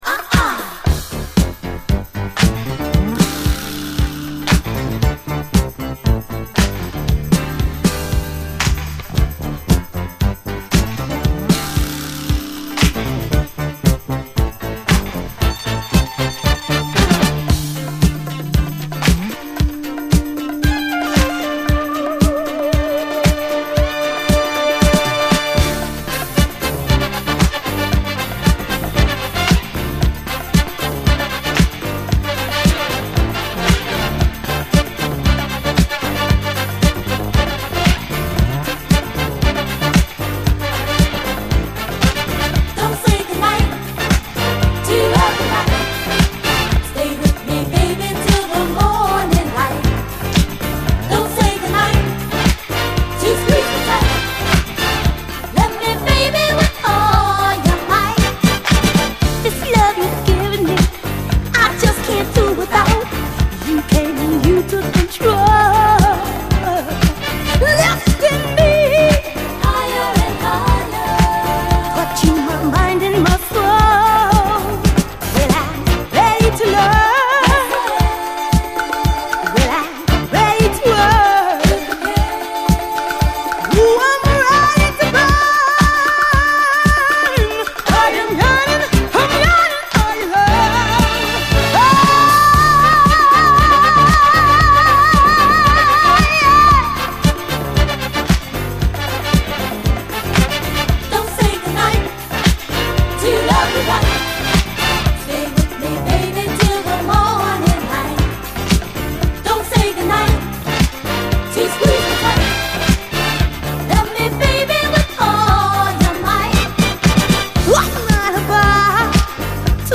SOUL, 70's～ SOUL, DISCO
ガラージ・クラシック！派手なブラス、そして、ガラージど真ん中な迫力のヴォーカル！
派手なブラス、そして、ガラージど真ん中な迫力のヴォーカルで盛り上げるグレイト・ダンサー！